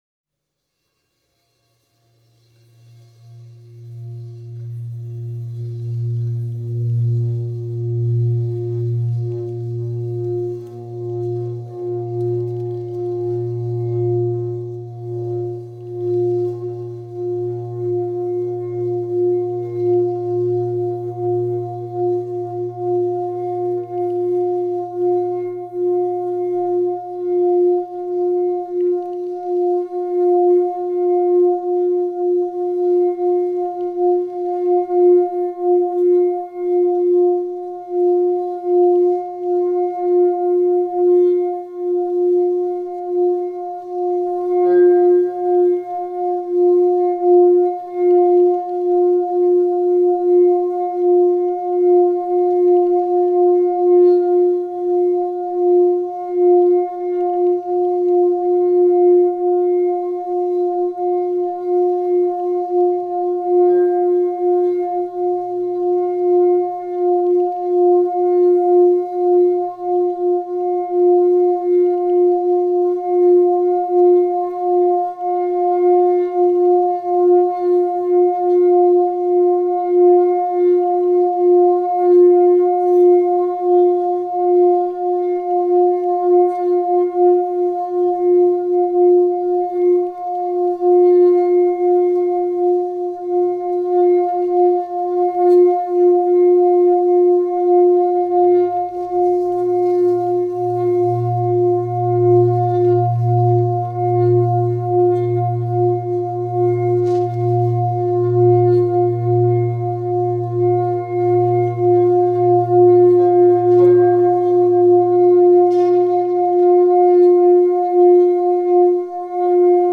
Музыка для медитации